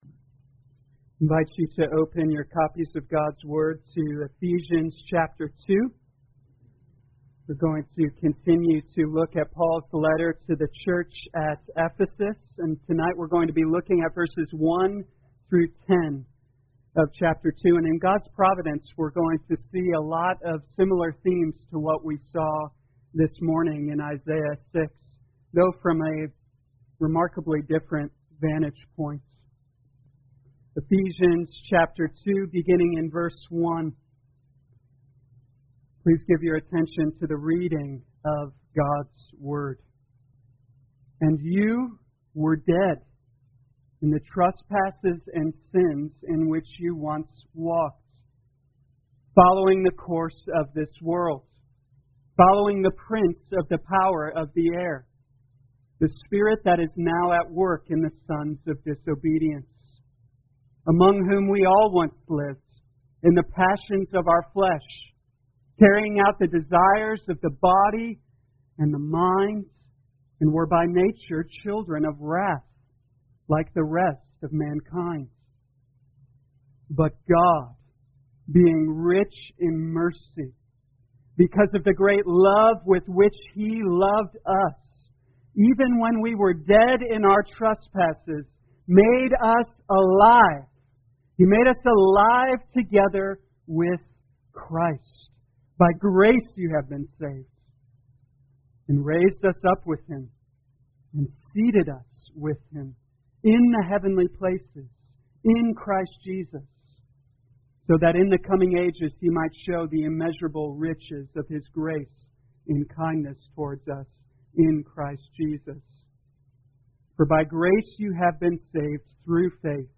2020 Ephesians Evening Service Download